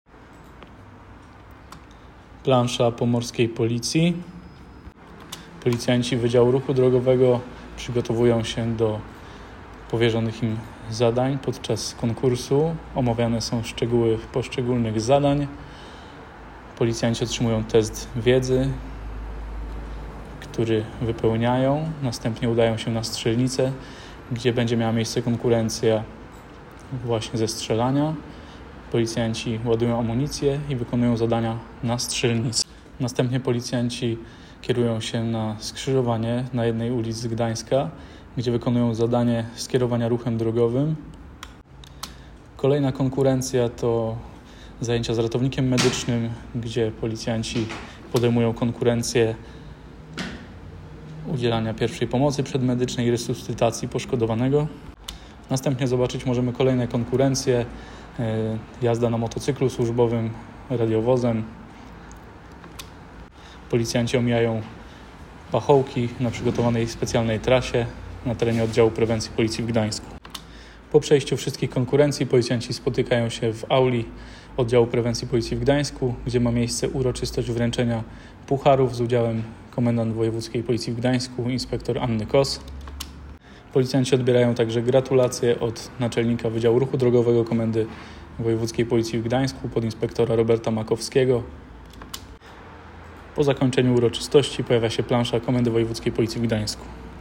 Nagranie audio Audiodyskrypcja